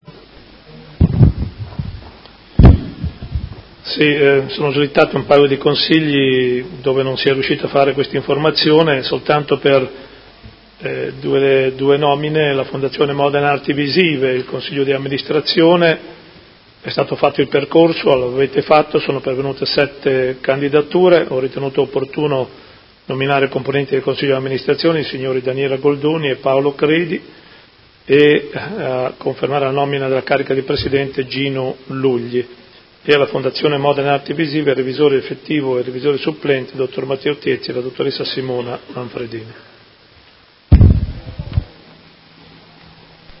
Seduta del 17/01/2019 Comunicazione.